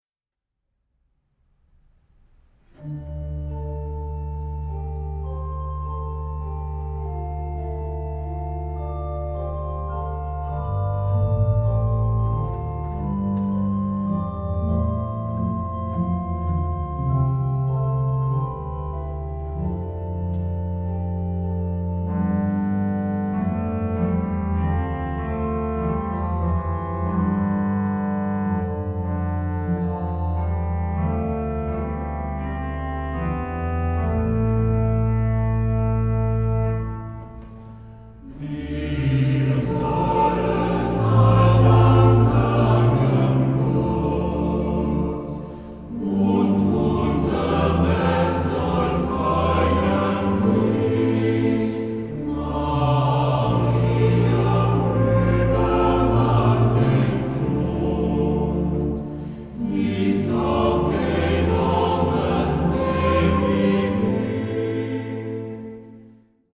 Genre-Stil-Form: Kirchenlied ; geistlich
Charakter des Stückes: zweizeitig
Chorgattung: SATB  (4 gemischter Chor Stimmen )
Tonart(en): a-moll